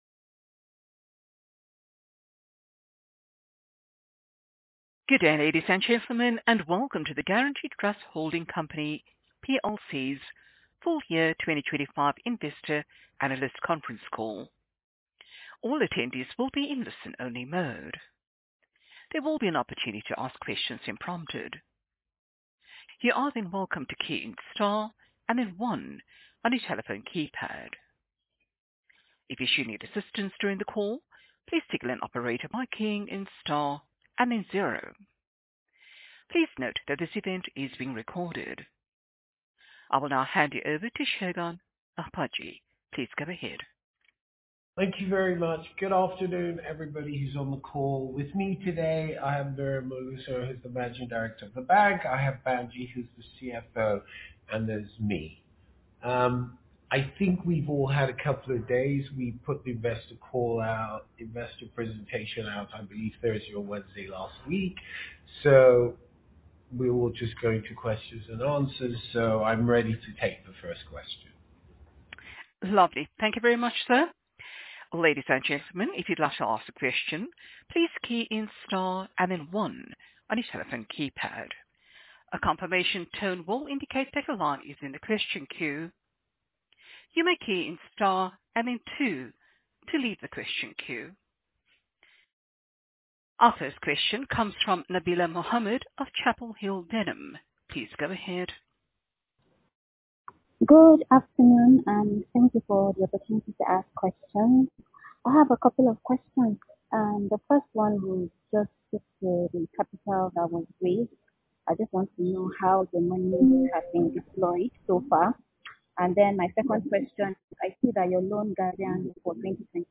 2025-Full-Year-Investor-Conference-Call.mp3